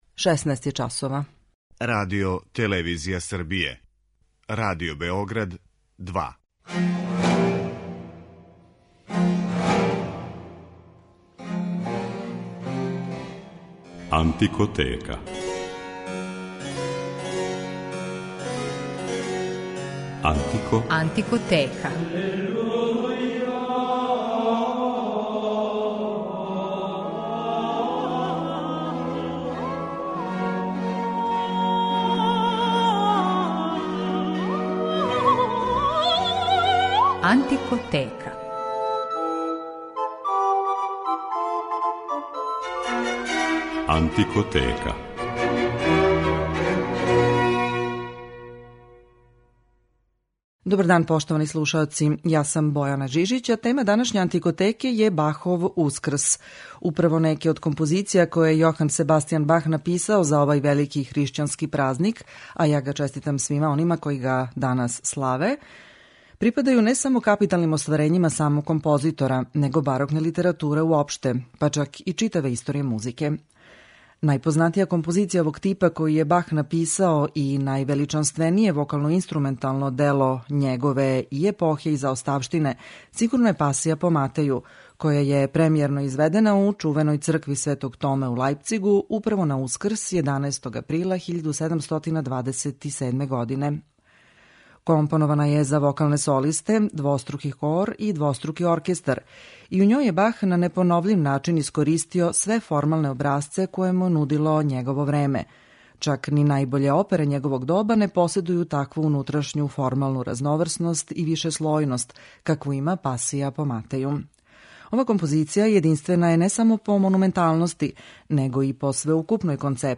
Њима је посвећена данашња Антикотека, чија је тема "Бахов Ускрс" и у њој ћете моћи да слушате најлепше фрагменте Бахове Пасије по Матеју и Пасије по Јовану.